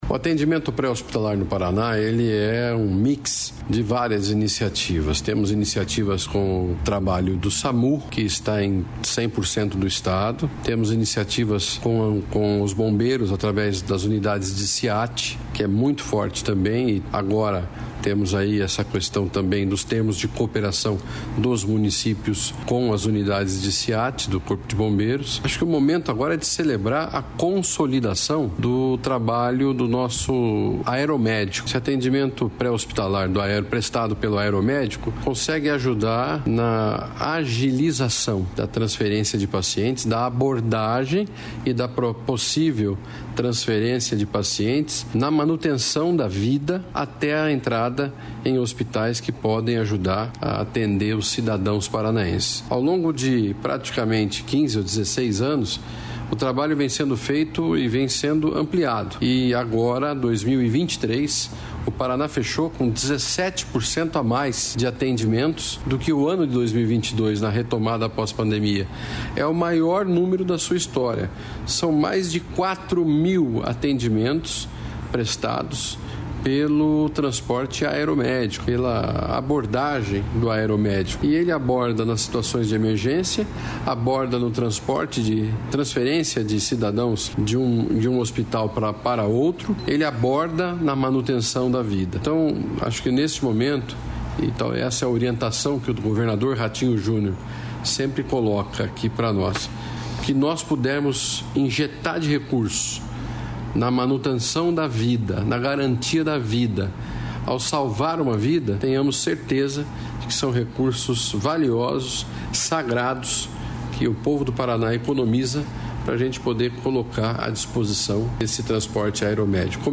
Sonora do secretário estadual da Saúde, Beto Preto, sobre o serviço aeromédico